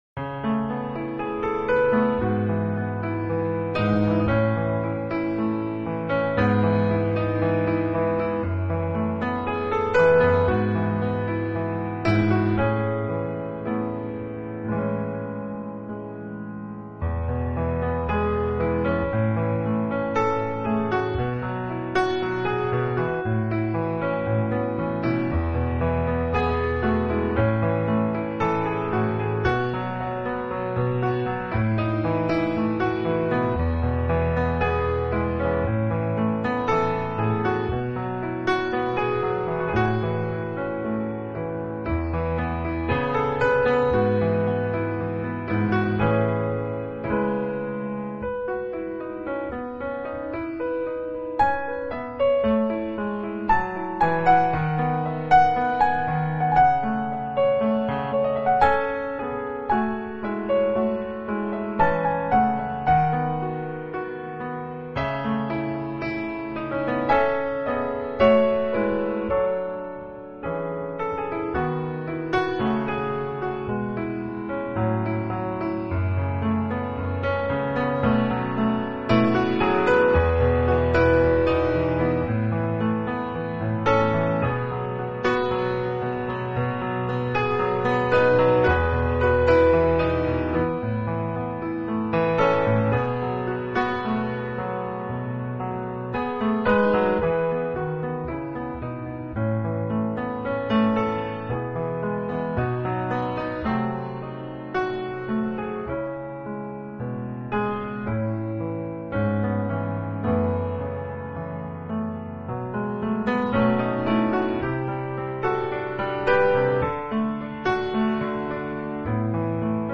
专辑流派：New Age
真挚沉静的琴声，在音符间轻盈转换；旋律舒缓 飞扬的流泄而来。